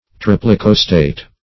Triplicostate \Trip`li*cos"tate\, a.
triplicostate.mp3